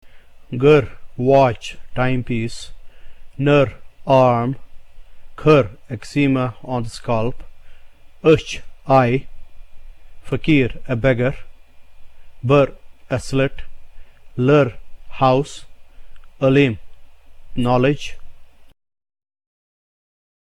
The third vowel has the same sound as the symbol "U" in the English word "CROCUS".